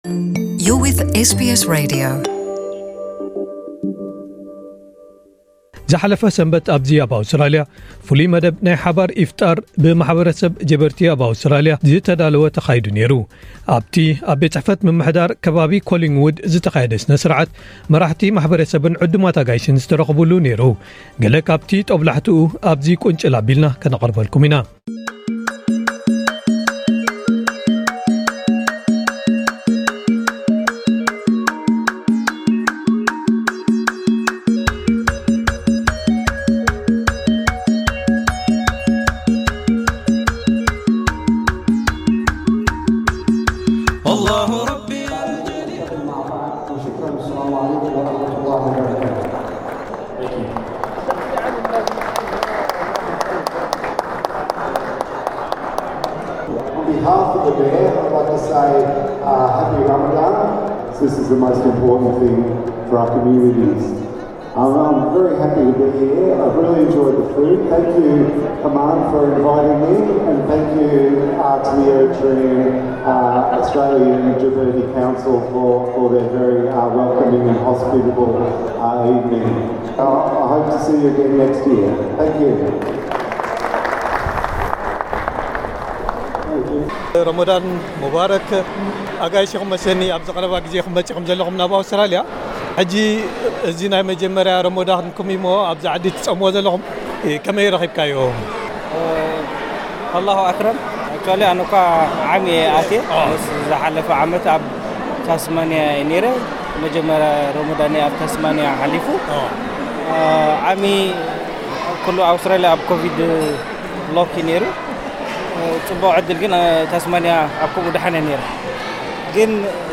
ዝሓለፈ ሰንበት ኣብ’ዚ ኣብ ኣውስትራልያ ፍሉይ መደብ ናይ ሓባር ኢፍጣር ብማሕበረሰብ ጀበርቲ ኣብ ኣውስትራልያ ዝተዳለወ ተኻይዱ ኔሩ። ኣብ’ቲ ኣብ ቤት ጽሕፈት ምምሕዳር ኮሊንግዉድ ዝተኻየደ ስነስርዓት መራሕቲ ማሕበረሰብን ዕዱማት ኣጋይሽን ዝተረኽብሉ ኔሩ፡ ገለ ካብ'ቲ ጦብላሕታቱ አብ'ዚ ቁንጭል አቢልና ነቅርበልኩም።